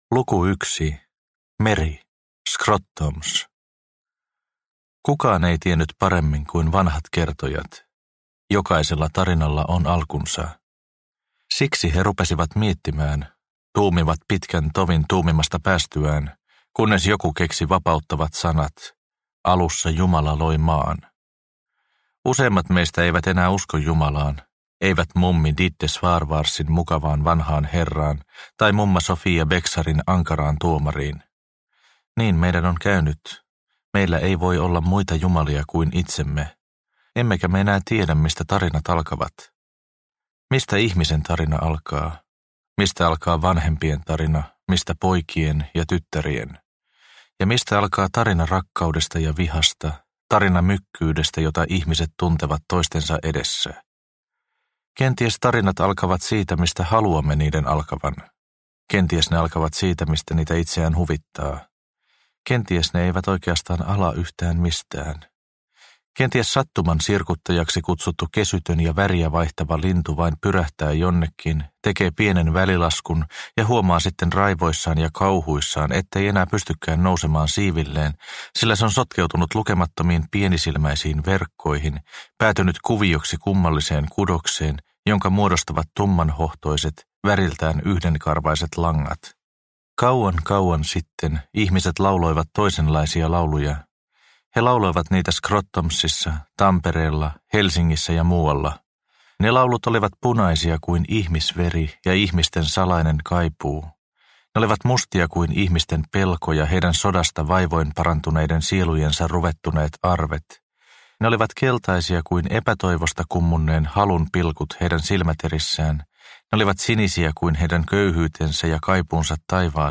Leijat Helsingin yllä – Ljudbok – Laddas ner